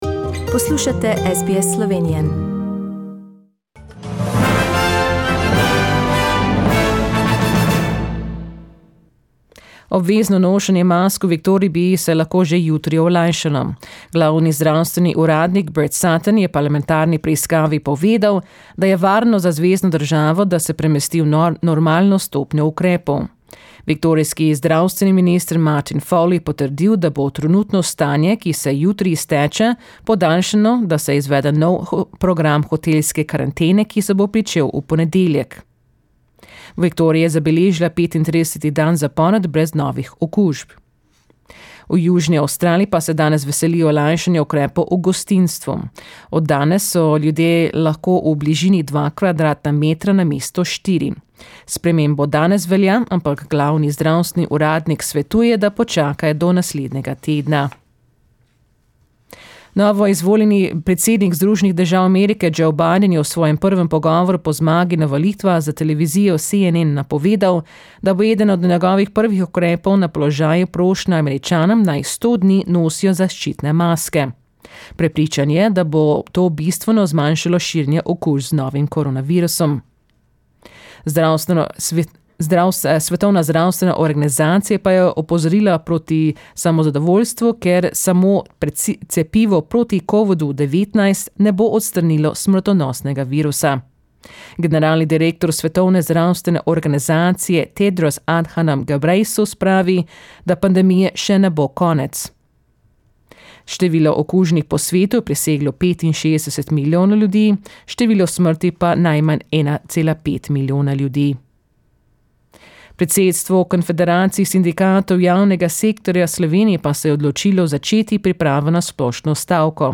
Today's news bulletin from the World, Slovenia and Australia in Slovenian.